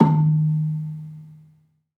Gamelan Sound Bank
Gambang-D#2-f.wav